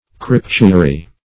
Search Result for " crypturi" : The Collaborative International Dictionary of English v.0.48: Crypturi \Cryp*tu"ri\ (kr[i^]p*t[=u]"r[imac]), n. pl.